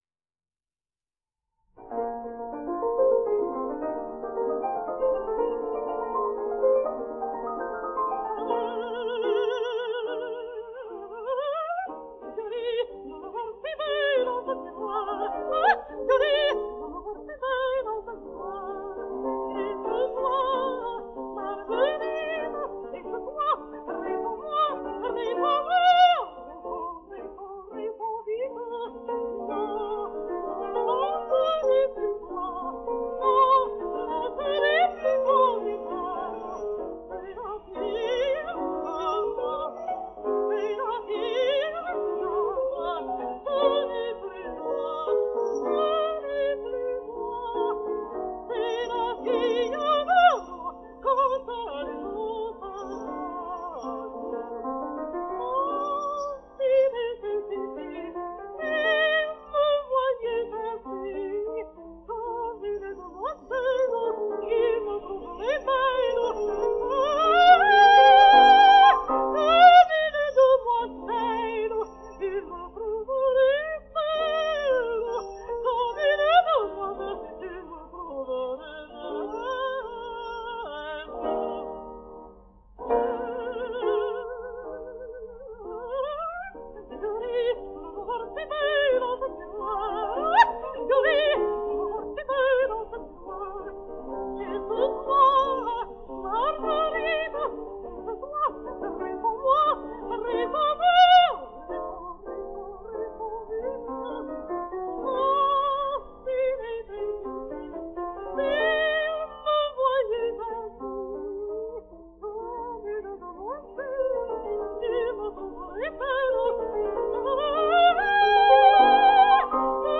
denoised.wav